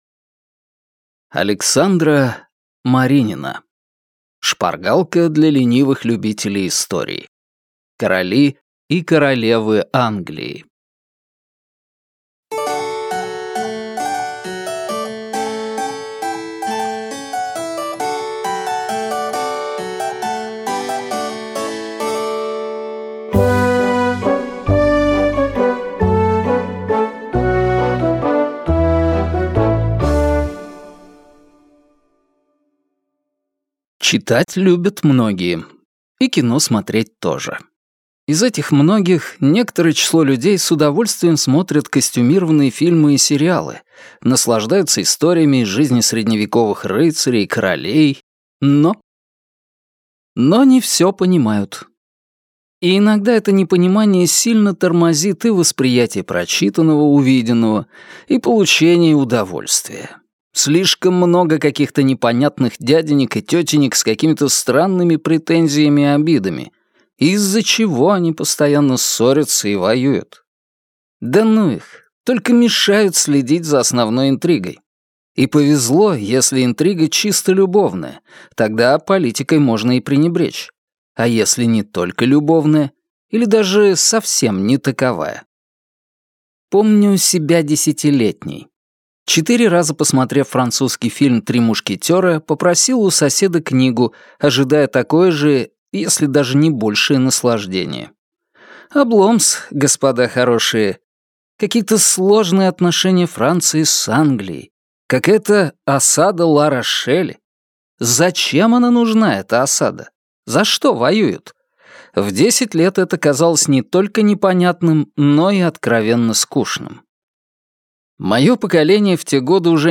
Аудиокнига Шпаргалка для ленивых любителей истории. Короли и королевы Англии | Библиотека аудиокниг